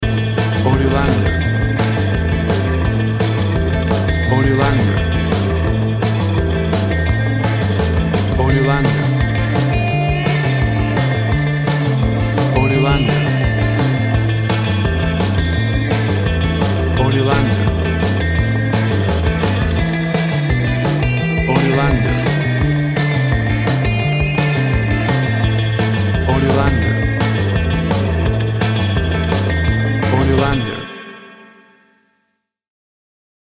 WAV Sample Rate 16-Bit Stereo, 44.1 kHz
Tempo (BPM) 160